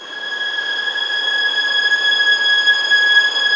Index of /90_sSampleCDs/Roland LCDP13 String Sections/STR_Violins II/STR_Vls6 p wh%